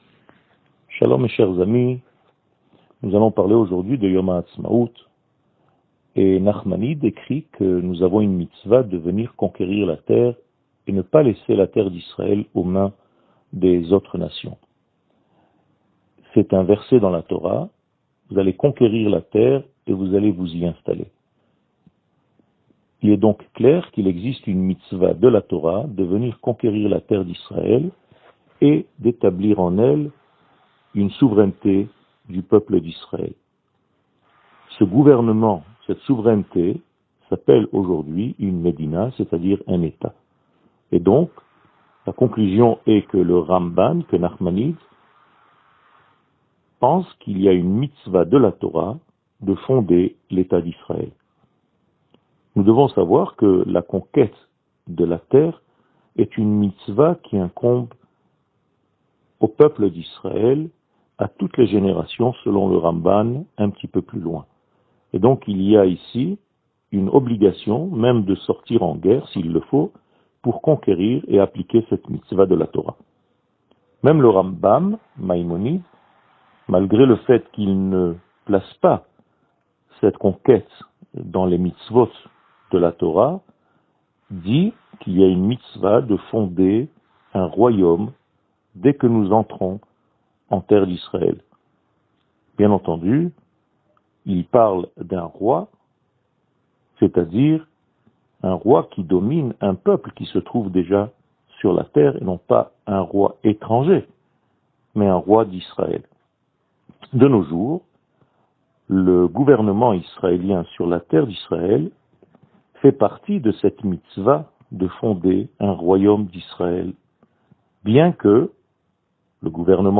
שיעור מ 15 אפריל 2021